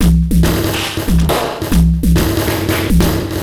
E Kit 04.wav